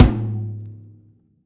drum-hitfinish.wav